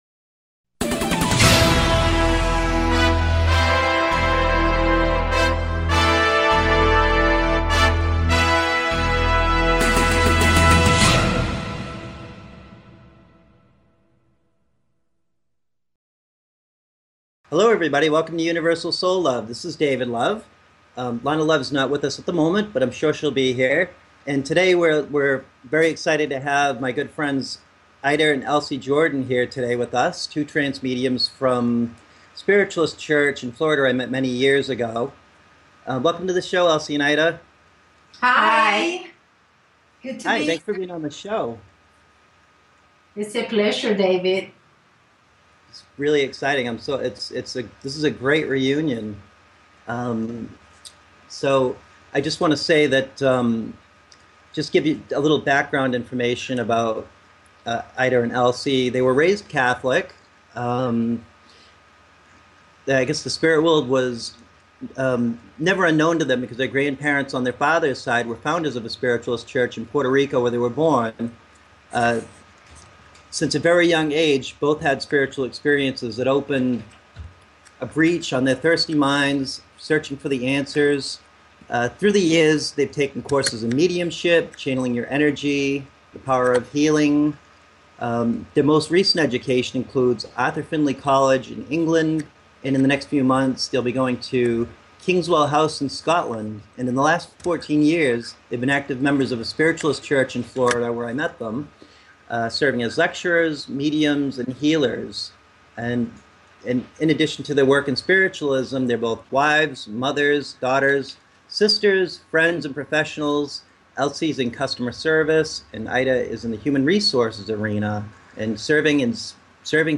Headlined Show, Hungry for the Truth June 23, 2015